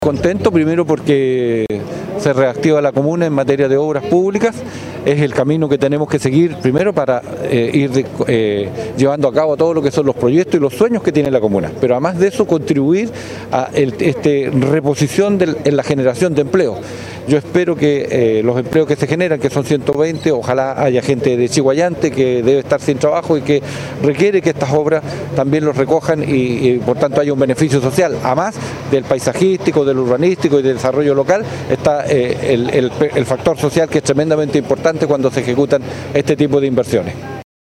Se trata de una recuperación que tiene 120 días para ser ejecutada y que en su momento peak concentrará 20 puestos de trabajo, impulsando la reactivación económica comunal, tal como lo indicó el diputado Gastón Saavedra.